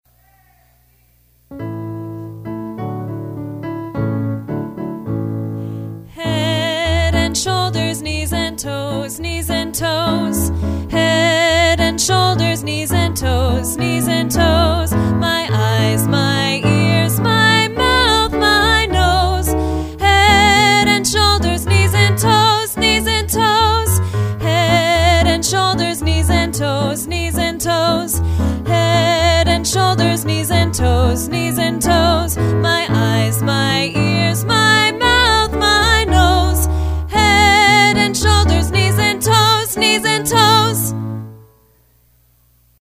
Head-and-Shoulders-vocal_demo.mp3